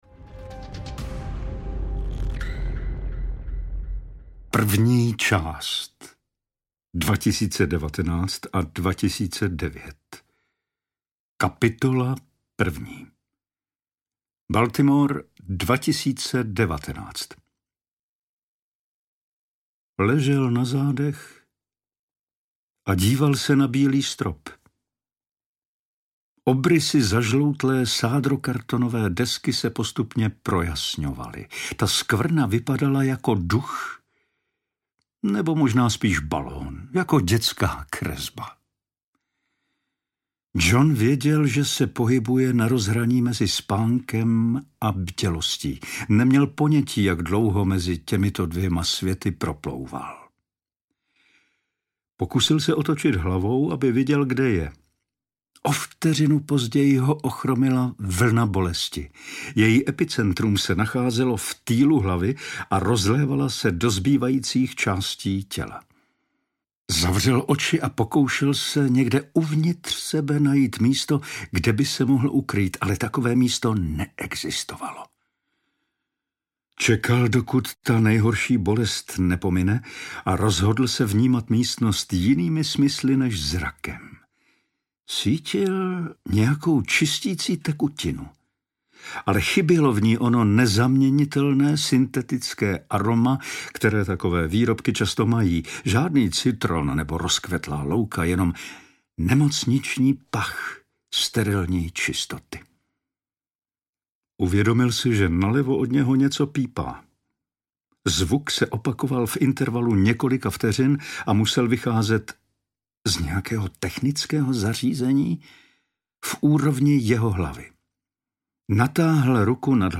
Ukázka z knihy
• InterpretPavel Soukup